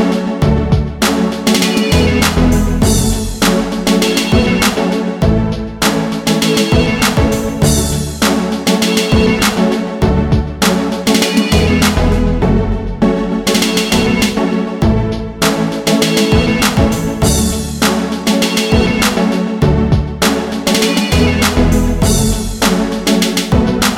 No Rap R'n'B / Hip Hop 4:28 Buy £1.50